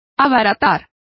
Complete with pronunciation of the translation of cheapening.